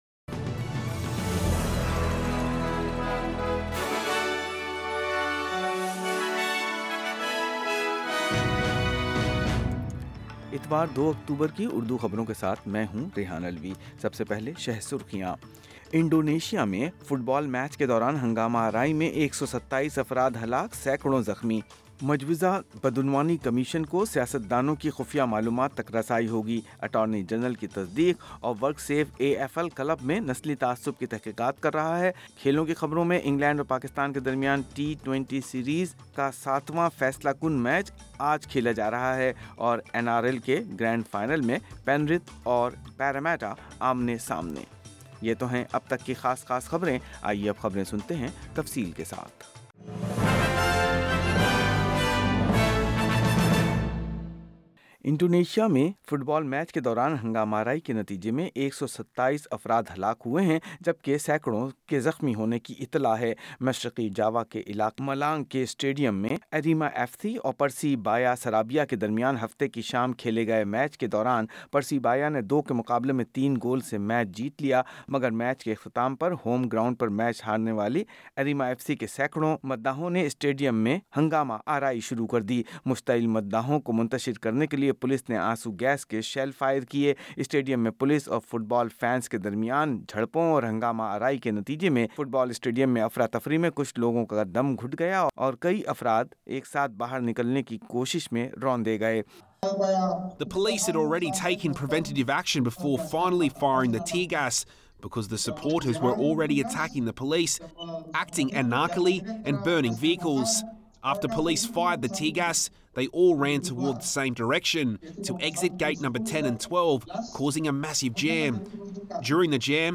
Full news bulletin in Urdu - Sunday 2nd. October 2022